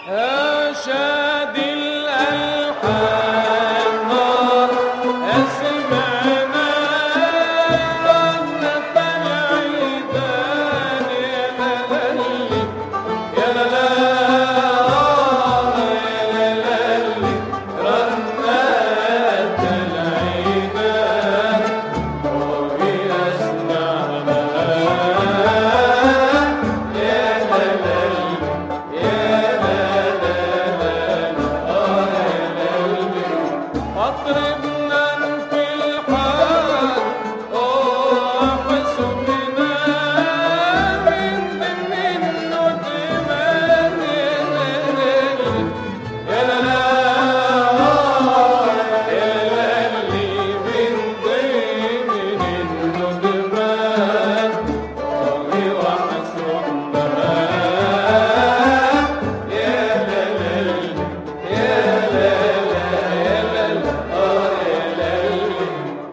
وهذا مقطع من موشح " يا شادي الألحان" مسموعا بصوت صباح فخري على مقام الراست :